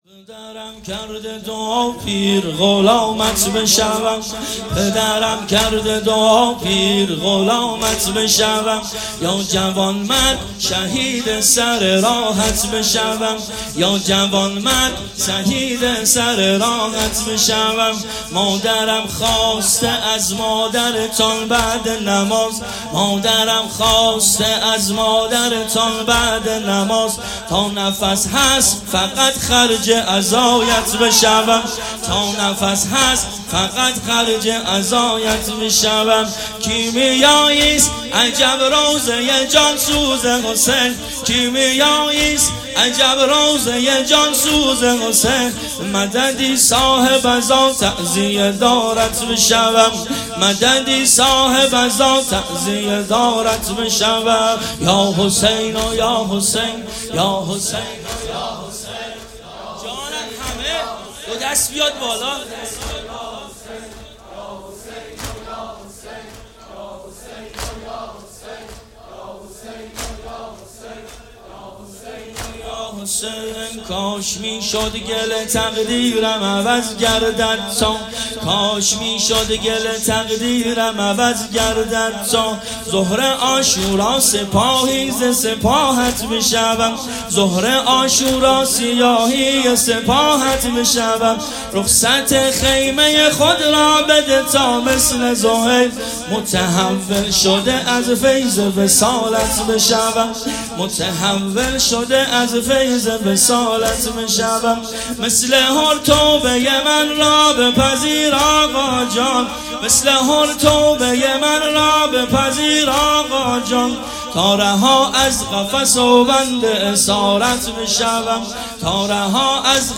واحد ، پدرم کرده دعا
محرم الحرام ۱۴۴۵